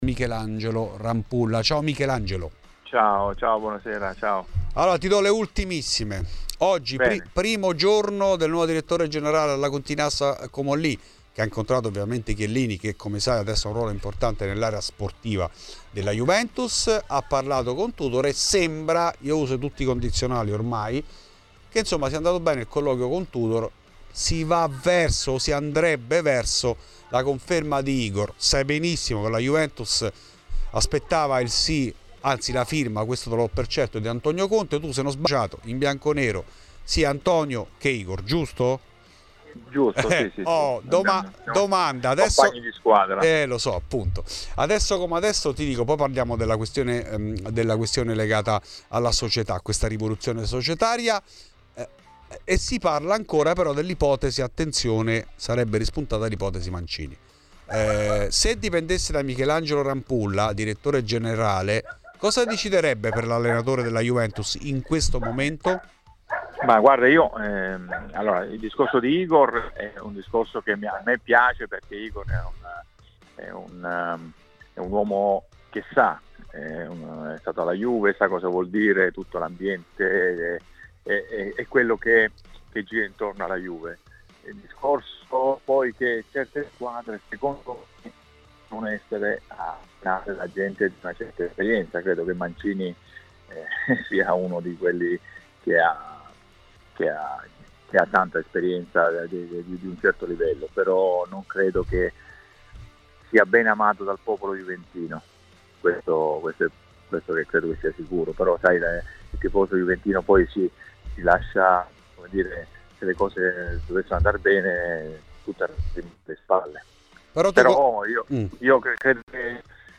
L'intervista integrale nel podcast